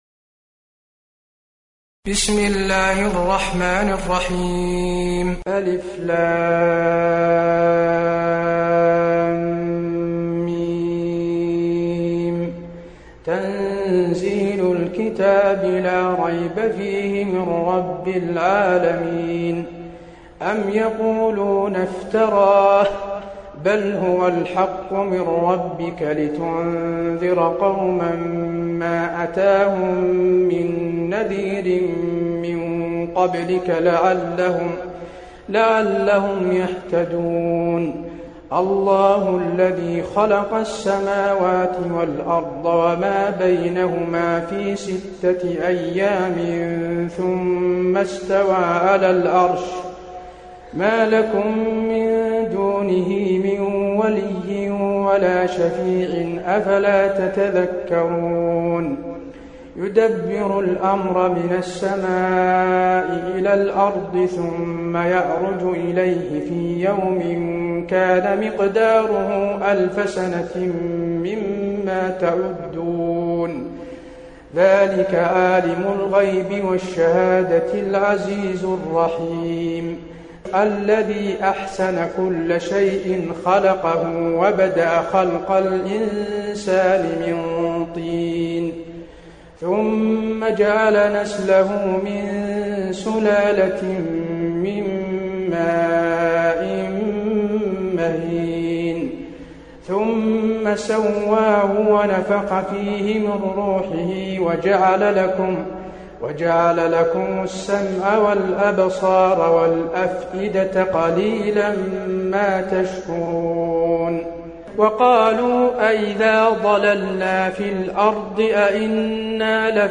المكان: المسجد النبوي السجدة